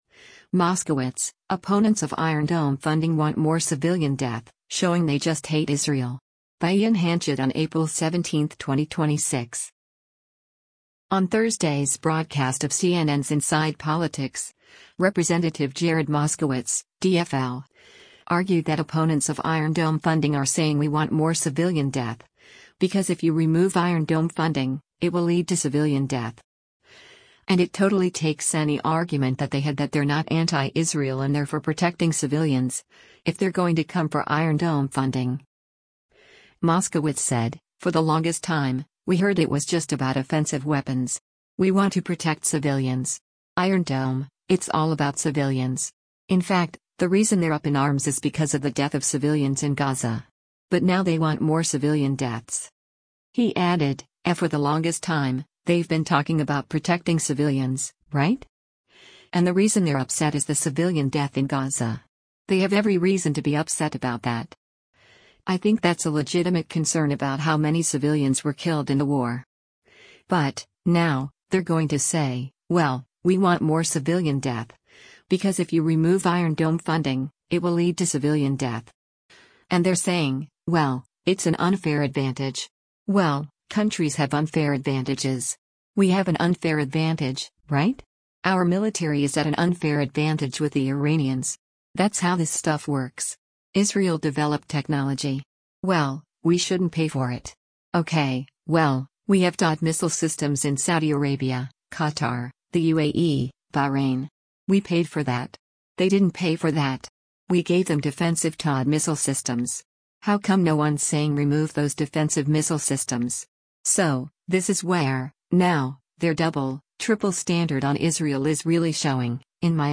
On Thursday’s broadcast of CNN’s “Inside Politics,” Rep. Jared Moskowitz (D-FL) argued that opponents of Iron Dome funding are saying “we want more civilian death, because if you remove Iron Dome funding, it will lead to civilian death.”